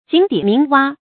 井底鳴蛙 注音： ㄐㄧㄥˇ ㄉㄧˇ ㄇㄧㄥˊ ㄨㄚ 讀音讀法： 意思解釋： 猶井蛙。